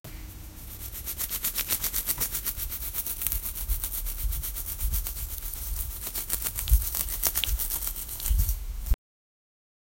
applying-oil-to-a-chicken-q7eu2uom.wav